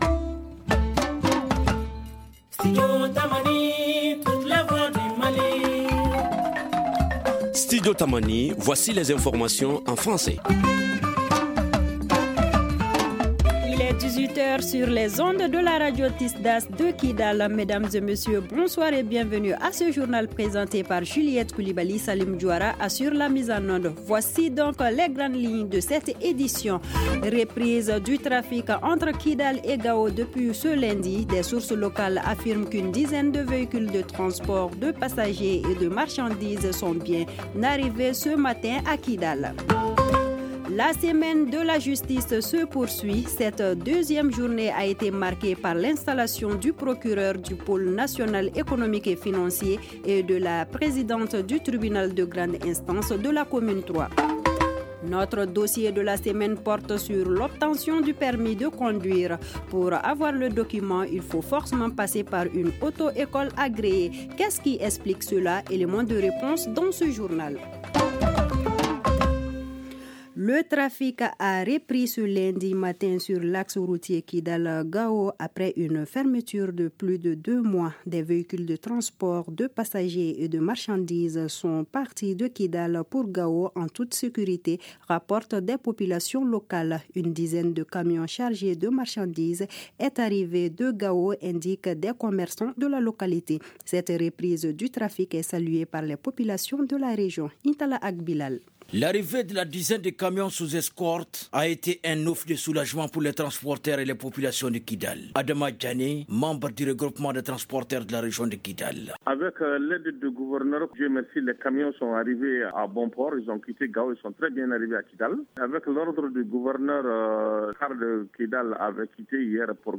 Éléments de réponses dans ce journal.